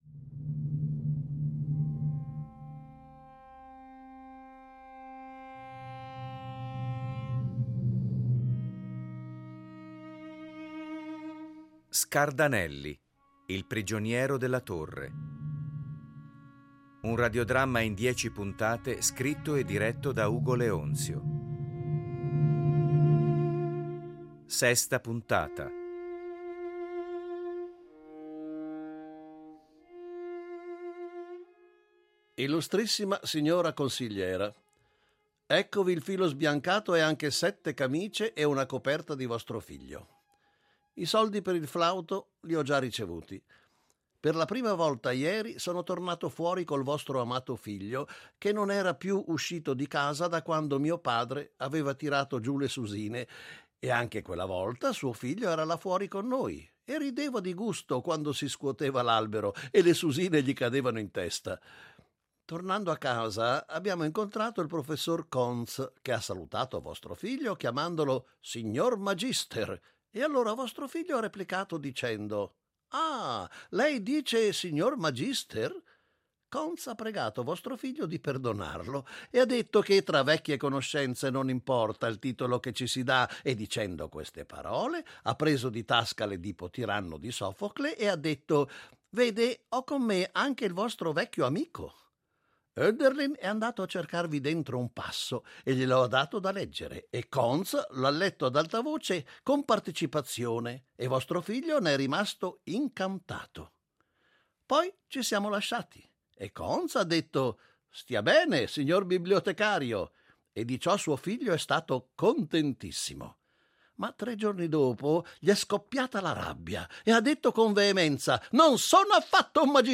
Editing e sonorizzazione